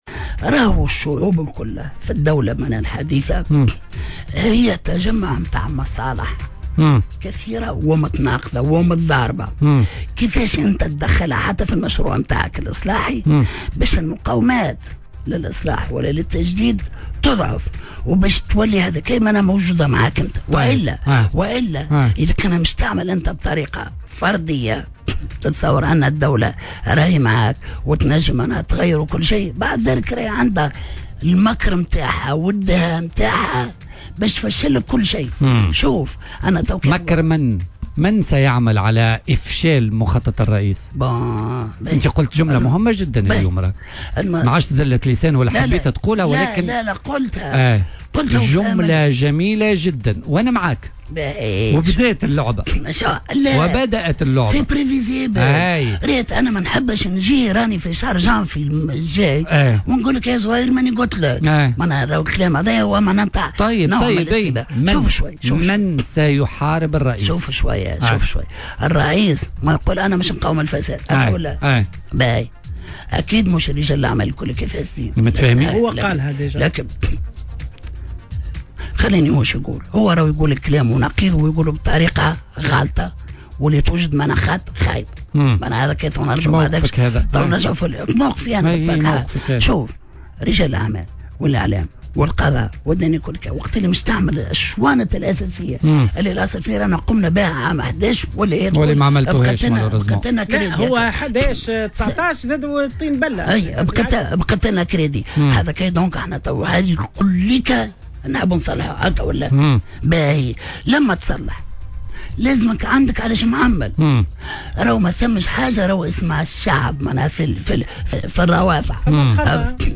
أكدّ القيادي السابق بحركة النهضة عبد الحميد الجلاصي ضيف برنامج بوليتكا على موجات الجوهرة، أنّ الشعوب في الدول الحديثة هي تجمّع مصالح كثيرة ومتناقضة ومتضاربة، يُمكن أن تكون جزء من مشروع إصلاحي، كما يمكن لها في المقابل أن تعمل على إفشال أيّ مخطط باستعمال المكر والدهاء لإفشال كل شيء"، مشيرا إلى وُجود مَنْ يحاول إفشال مخطط رئيس الجمهورية قيس سعيّد، حسب تعبيره.